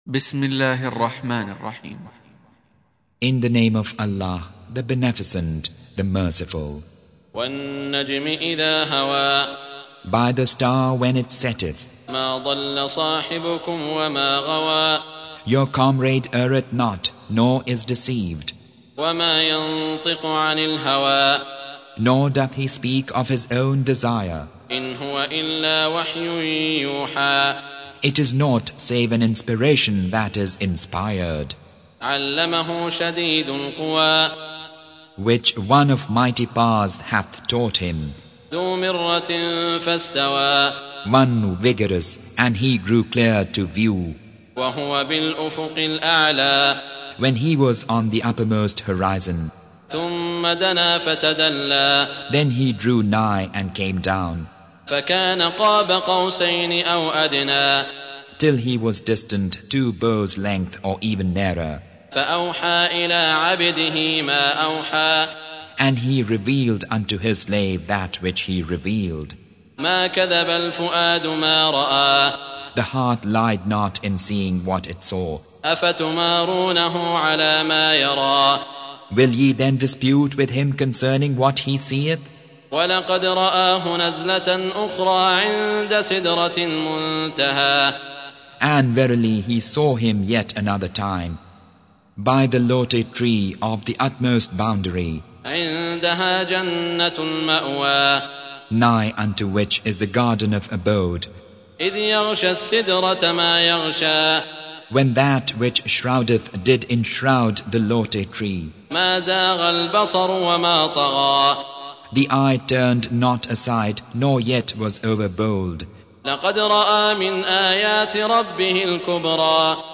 Surah Repeating تكرار السورة Download Surah حمّل السورة Reciting Mutarjamah Translation Audio for 53. Surah An-Najm سورة النجم N.B *Surah Includes Al-Basmalah Reciters Sequents تتابع التلاوات Reciters Repeats تكرار التلاوات